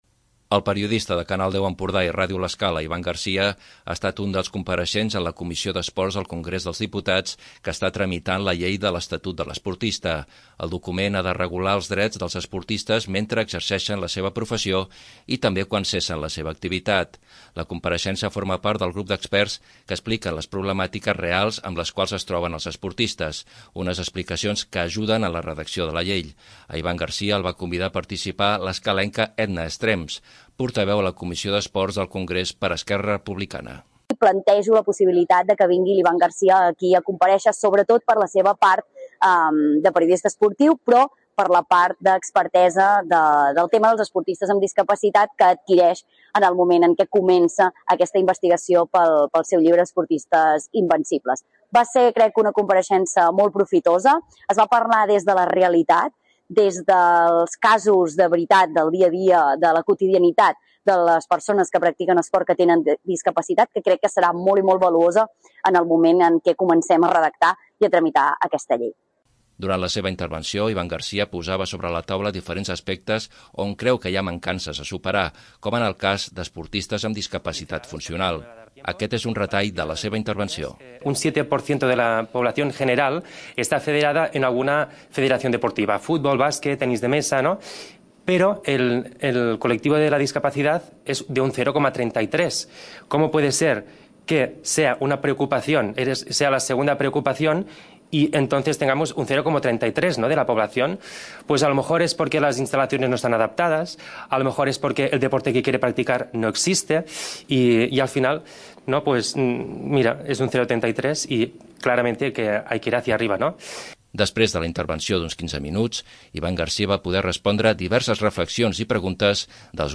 Aquest és un retall de la seva intervenció.